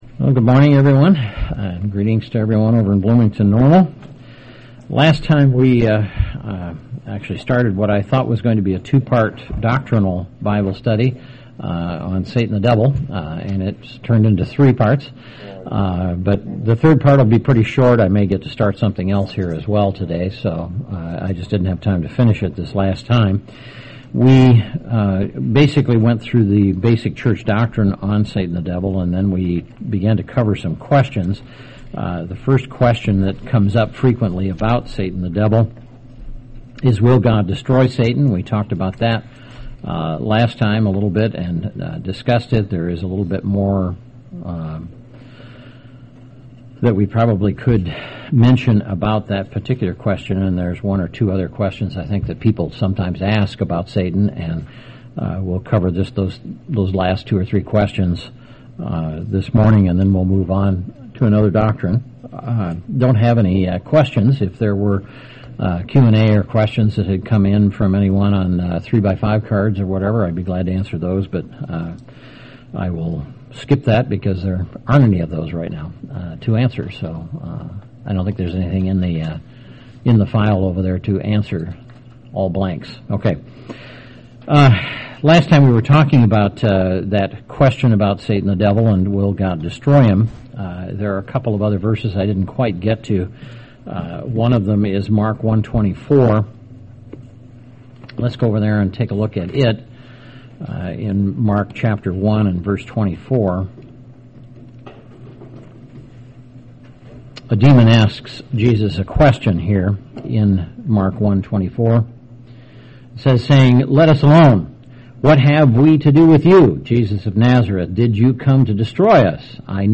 Conclusion of a topical Bible study on the UCG Fundamental Belief, the doctrine of Satan the Devil.